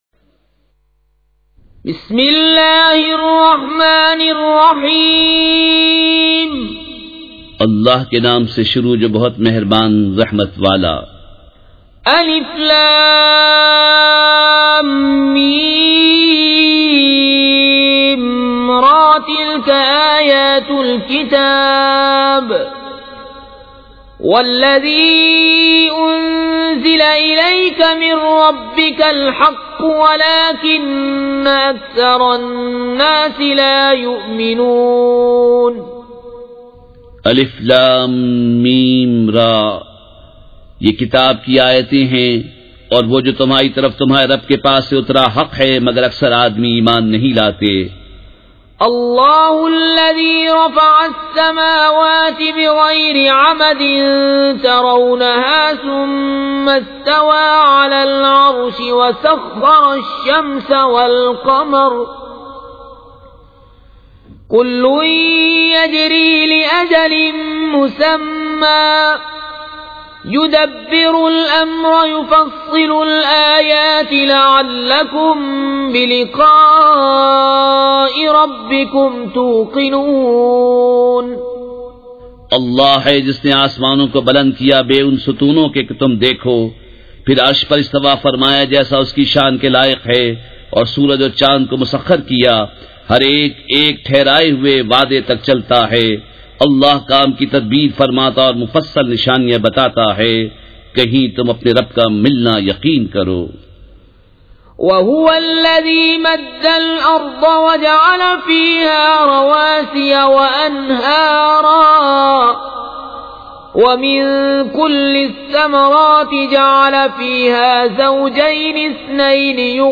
سورۃ الرعد مع ترجمہ کنزالایمان ZiaeTaiba Audio میڈیا کی معلومات نام سورۃ الرعد مع ترجمہ کنزالایمان موضوع تلاوت آواز دیگر زبان عربی کل نتائج 1818 قسم آڈیو ڈاؤن لوڈ MP 3 ڈاؤن لوڈ MP 4 متعلقہ تجویزوآراء
surah-e-raad-with-urdu-translation.mp3